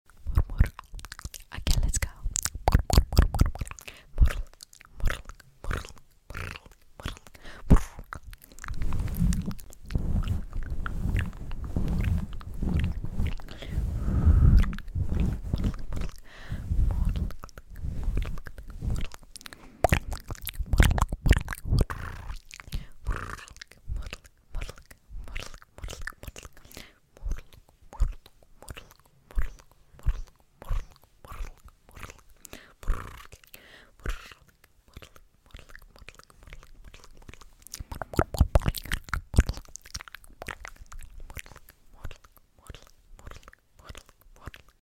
Asmr pur pur mur myr sound effects free download